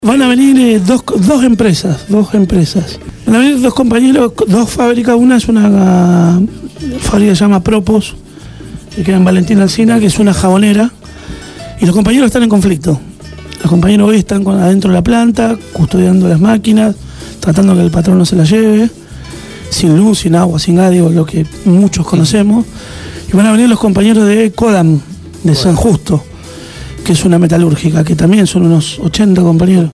Entrevista
en los estudios de Radio Gráfica FM 89.3 con motivo del encuentro de trabajadores de empresas autogestionadas que se realizado el viernes 14 de octubre en la Cooperativa Gráfica Patricios.